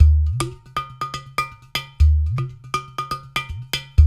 120 -UDU 0BL.wav